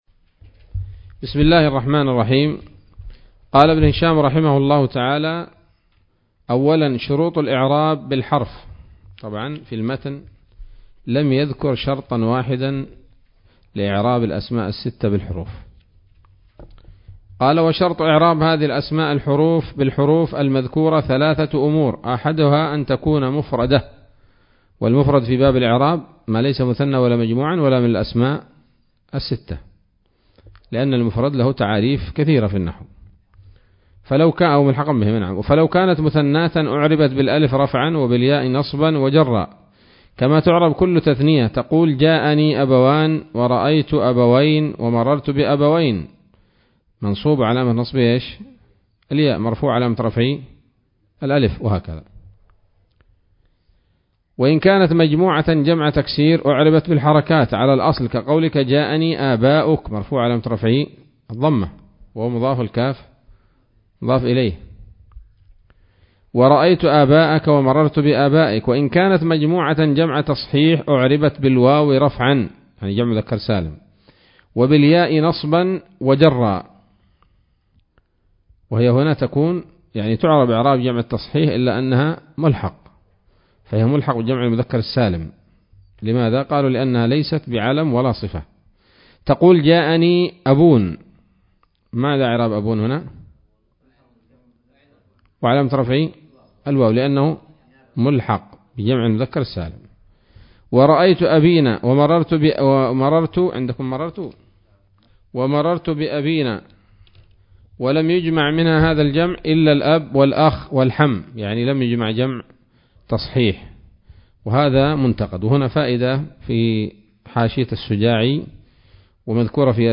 الدرس السادس عشر من شرح قطر الندى وبل الصدى [1444هـ]